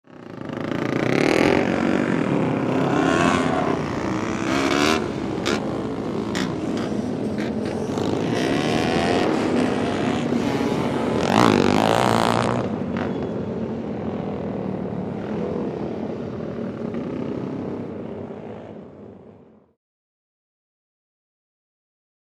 Dirt Bike | Sneak On The Lot
Motorcycle; Several Four Stroke Dirt Bikes Ride Around Track.